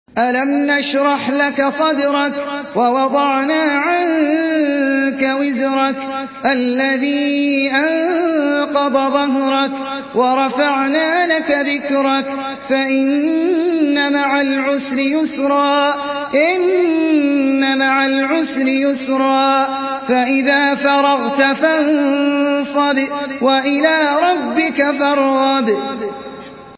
سورة الشرح MP3 بصوت أحمد العجمي برواية حفص
مرتل حفص عن عاصم